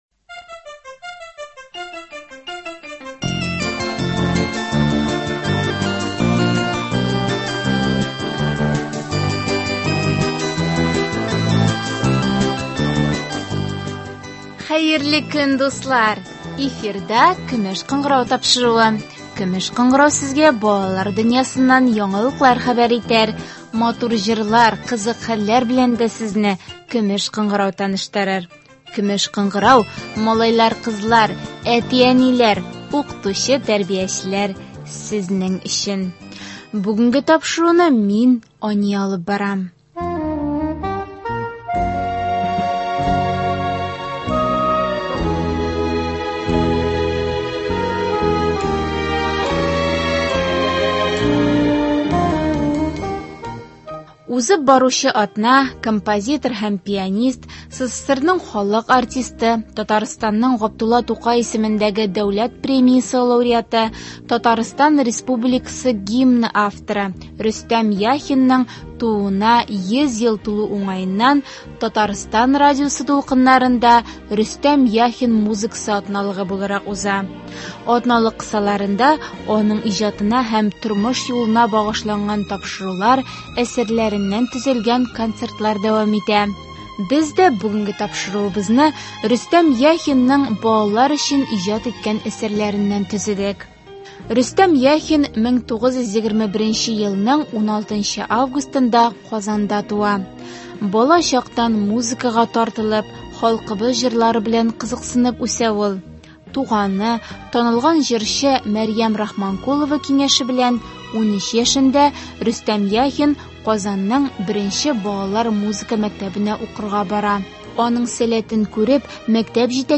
Бүгенге тапшыруыбызны композитор һәм пианист, СССРның халык артисты, Татарстанның Г. Тукай исемендәге дәүләт премиясе лауреаты, Татарстан Республикасы гимны авторы Рөстәм Яхинның тууына 100 ел тулу уңаеннан аның балалар өчен иҗат иткән әсәрләреннән төзедек. Радиобыз фондында композитор Рөстәм Яхинның балалар музыкасы турында сөйләгән язмасы да саклана.